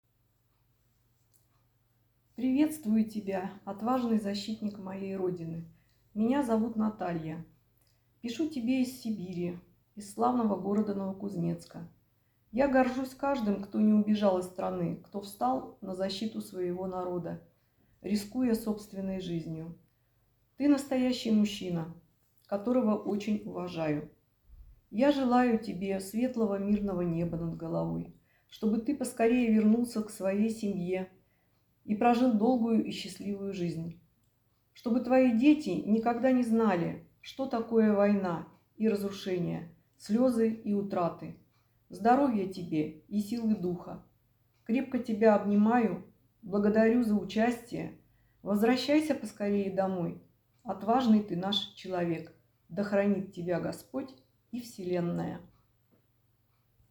Незрячие и слабовидящие читатели пишут «звуковые» письма в поддержку героев СВО.